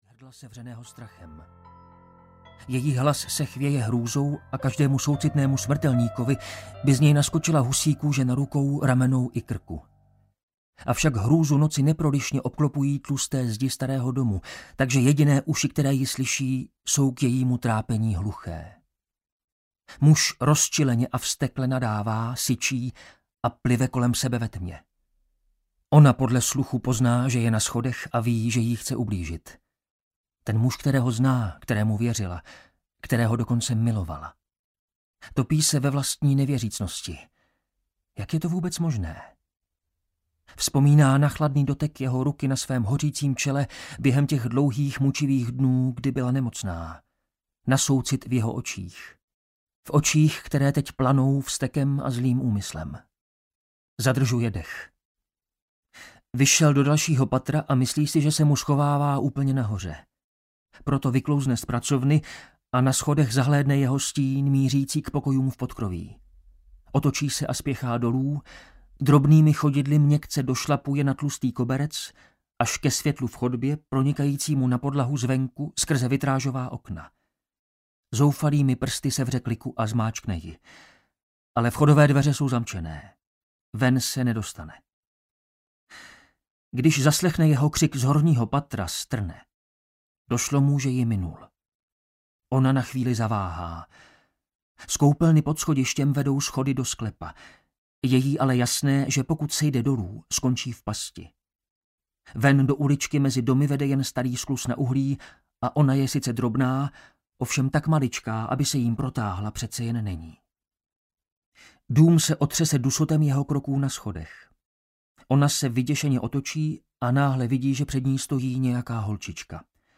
Karanténa audiokniha
Ukázka z knihy